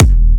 HUMBLE kick.wav